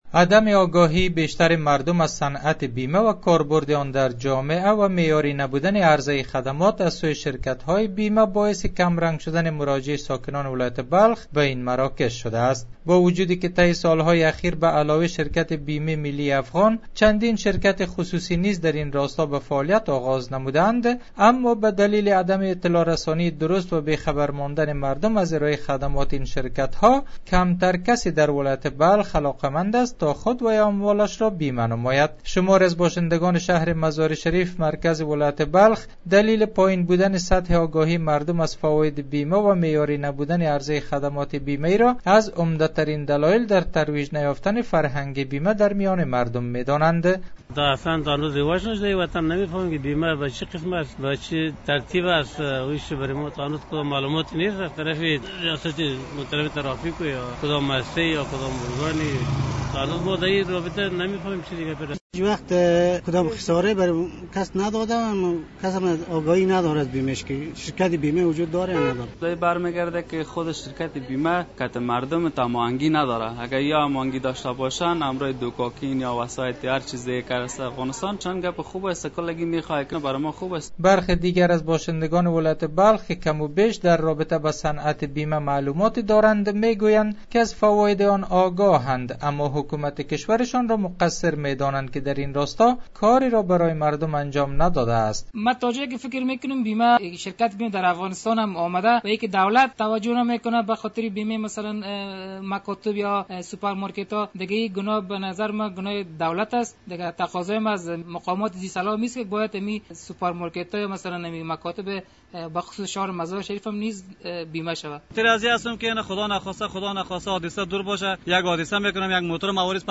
گزارشی